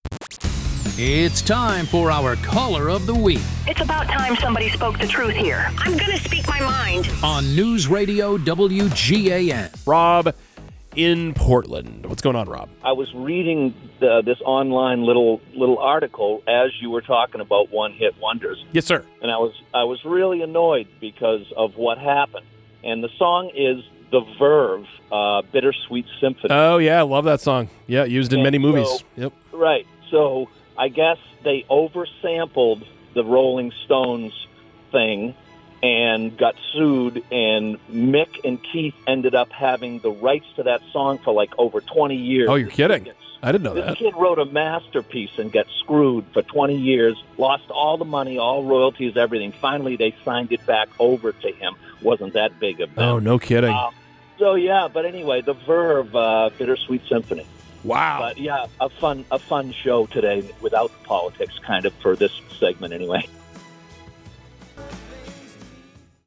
Caller Of The Week 9/26/25